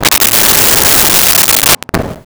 Wind Howl 02
Wind Howl 02.wav